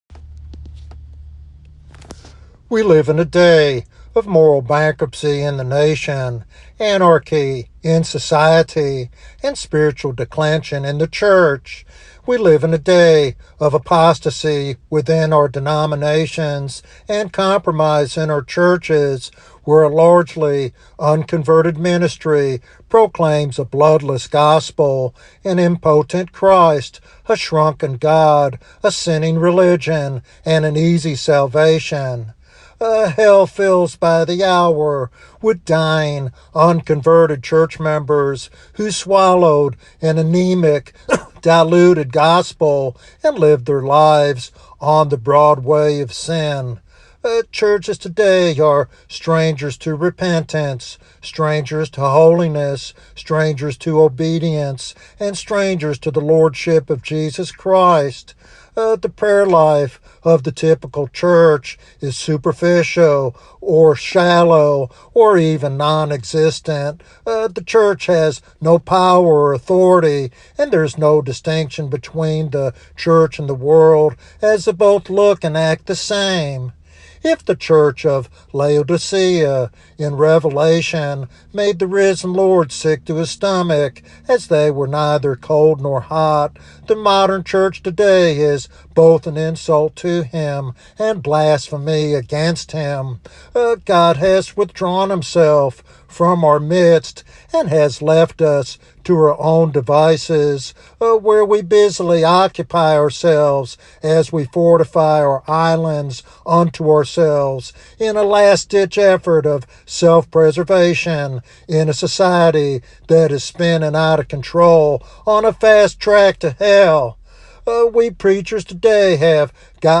This sermon is a passionate call to awaken the church to its calling and power through the blood of Christ.